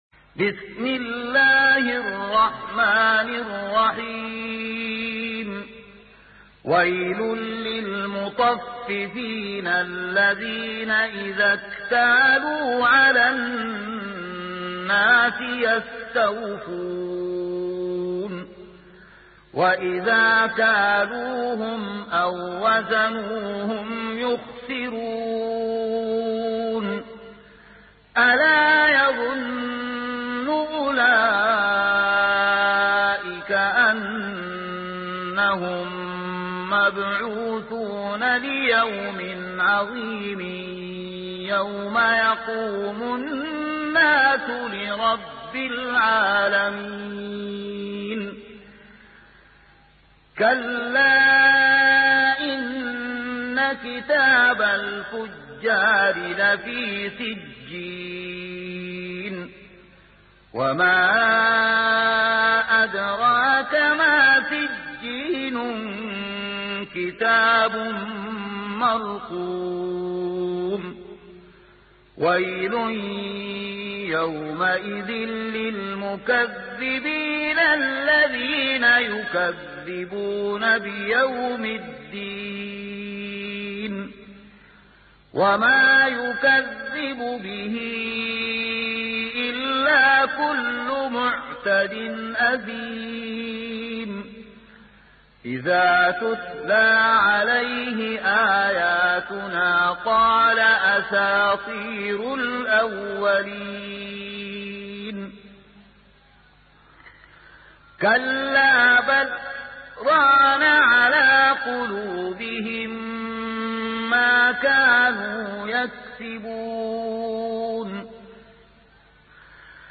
سورة المطففين | القارئ